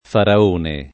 fara1ne] s. m. — come titolo dei re dell’antico Egitto, scritto talvolta con F‑ maiusc. (nell’uso biblico e in certe locuz.) in quanto inteso come n. pr.: fu indurito il cuore di F.; gallina di F. (com. gallina faraona o assol. faraona); serpente di F. — sim. i top. e cogn. Faraone, Faraoni, e il cogn. Faraon [fara1n]